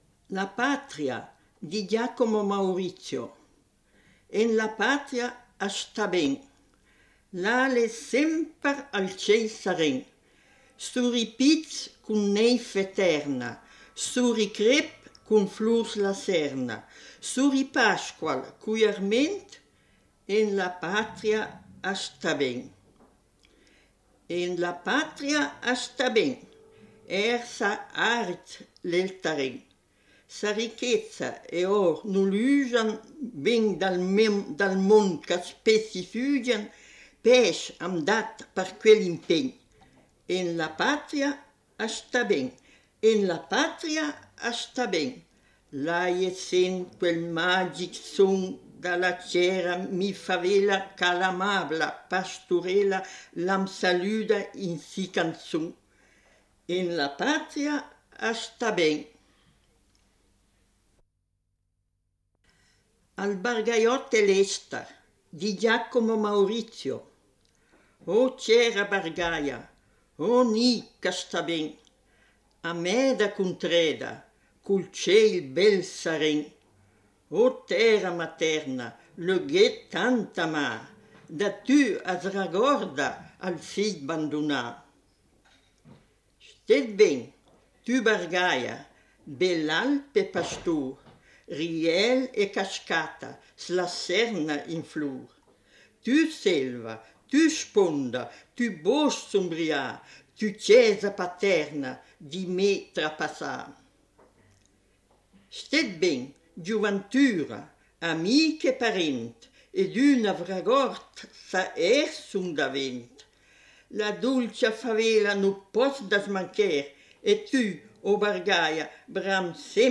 Lettura di poesie bregagliotte